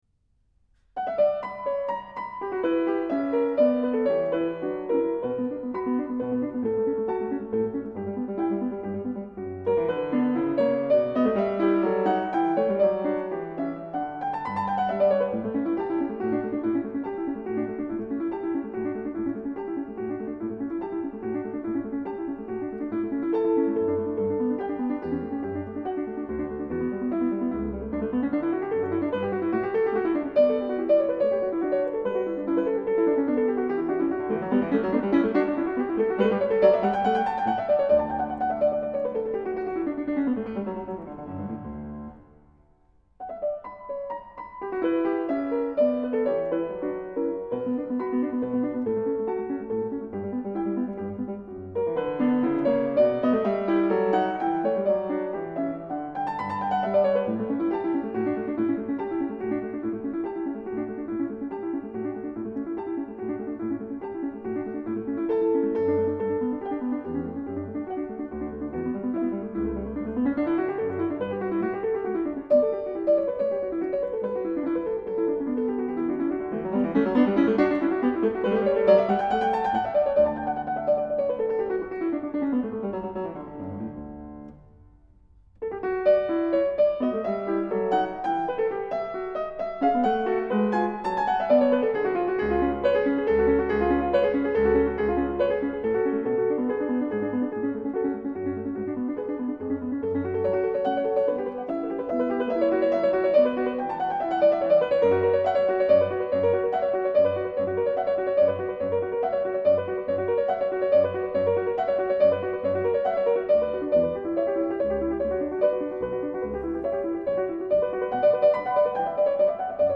piano Bösendorfer.
Enregistré à Jesus-Kirche de Berlin en 2018.